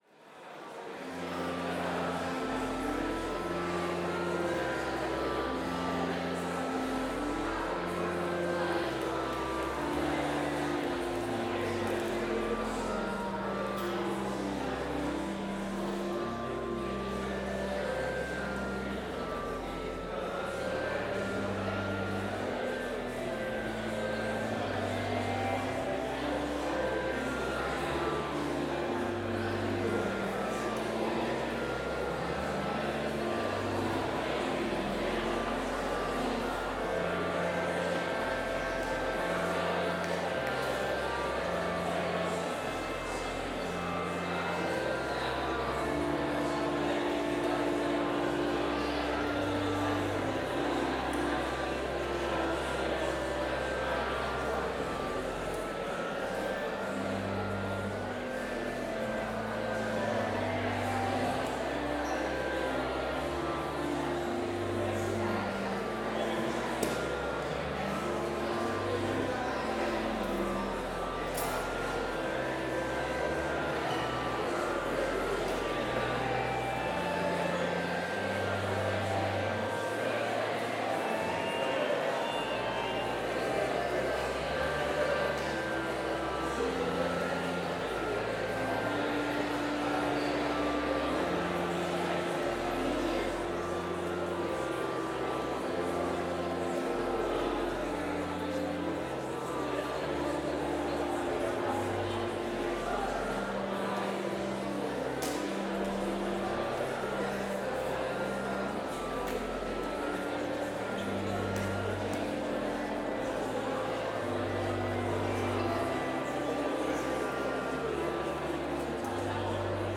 Complete service audio for Chapel - Friday, August 23, 2024
Watch Listen Complete Service Audio file: Complete Service Sermon Only Audio file: Sermon Only Order of Service Prelude Hymn 210 - Lord, Take My Hand and Lead Me View Reading: John 14:4-6 View And where I go you know, and the way you know.”
Devotion Prayer Hymn 206 - I Am Trusting Thee, Lord Jesus View Blessing Postlude